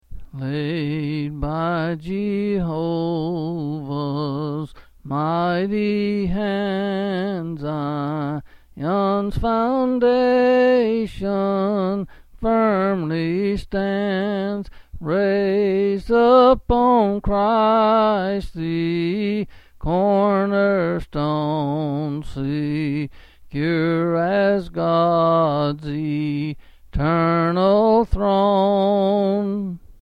Quill Selected Hymn
L. M.